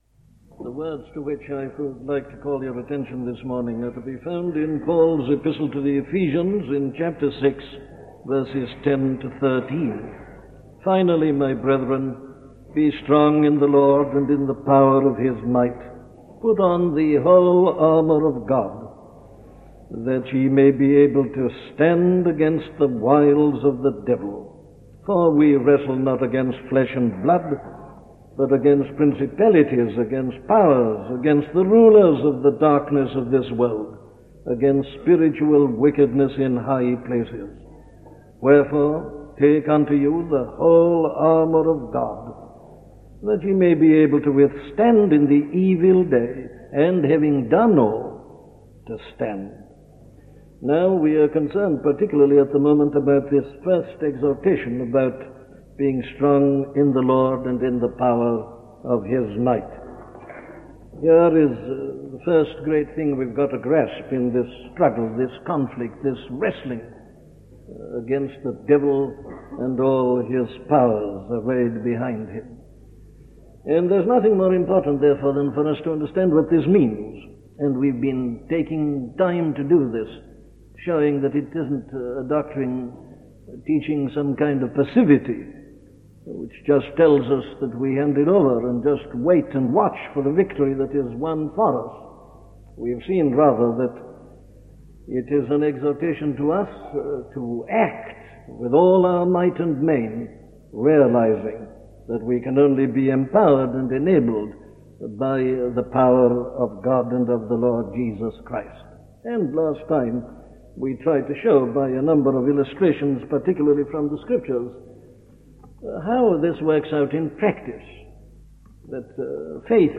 Things to Avoid - a sermon from Dr. Martyn Lloyd Jones
Listen to the sermon on Ephesians 6:10-13 'Things to Avoid' by Dr. Martyn Lloyd-Jones